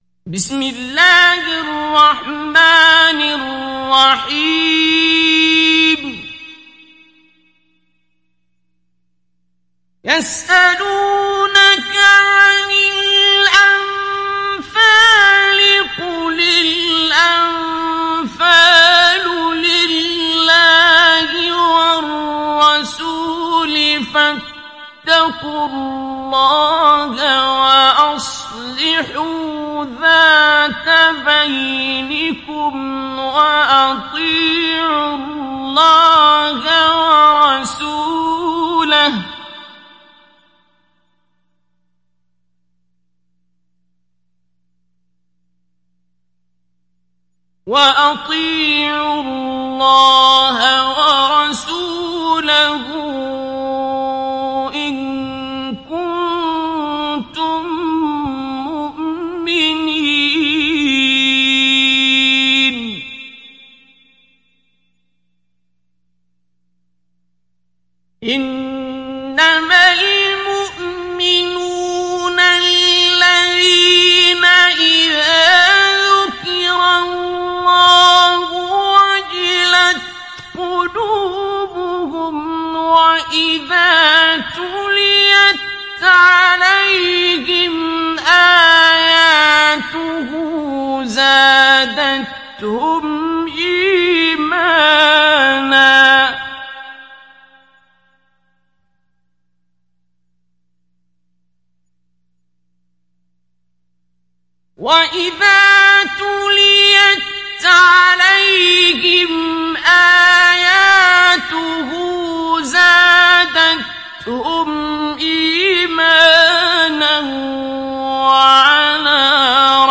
İndir Enfal Suresi Abdul Basit Abd Alsamad Mujawwad